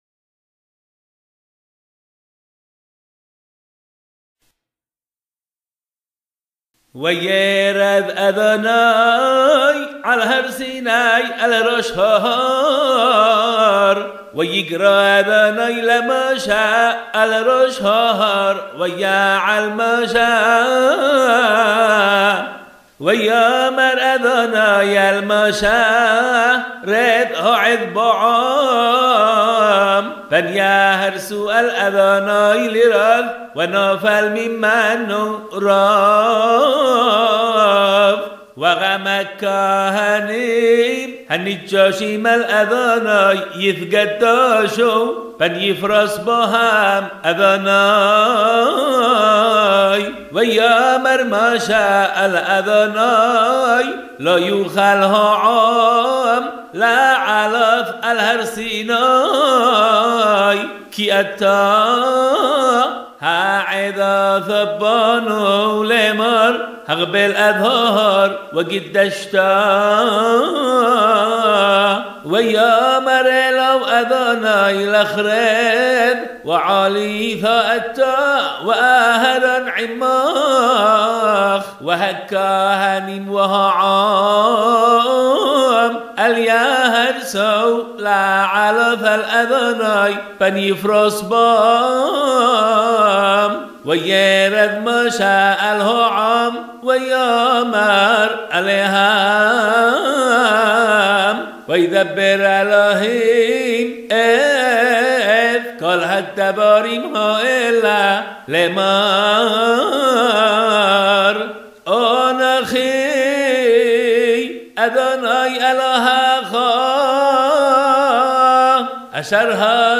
קריאה בנוסח תימן
קריאה בתורה - עשרת הדברות, פרשת יתרו.